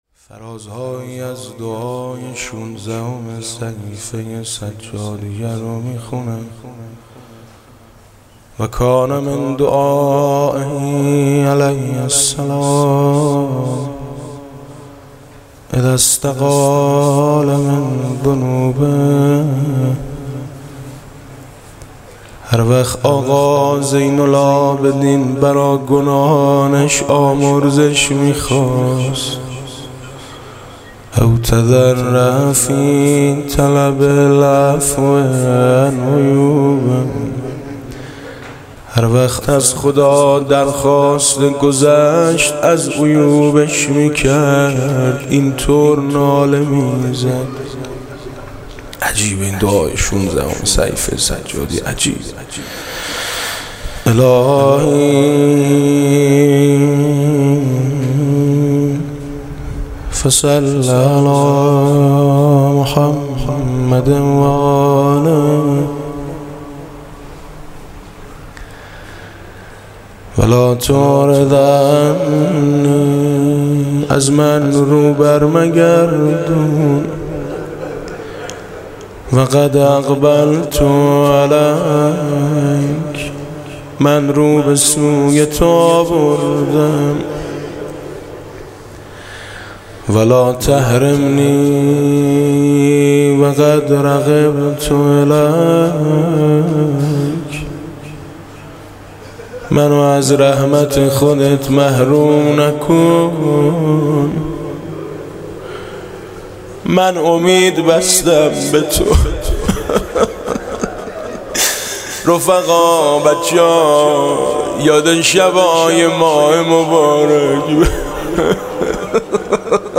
جلسه هفتگی مورخ 94/05/08 هیأت شهدای گمنام به مناسبت